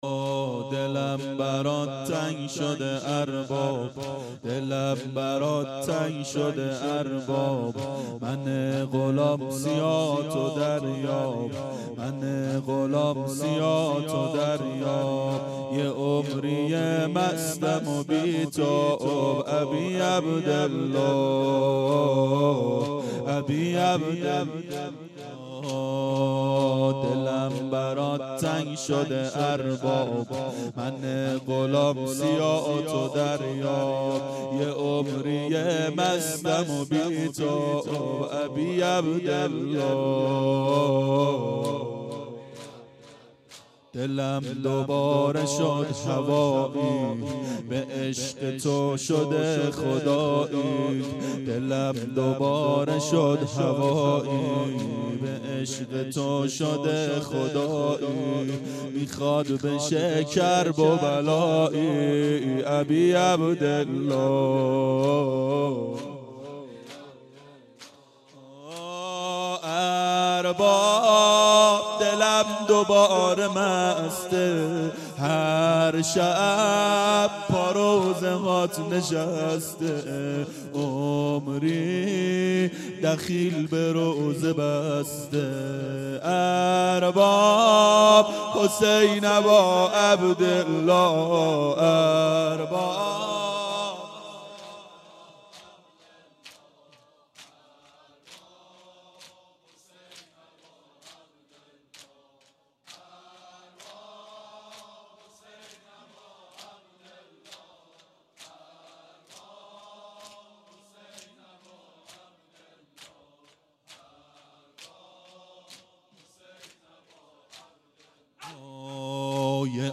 • شب اول محرم 92 هیأت عاشقان اباالفضل علیه السلام منارجنبان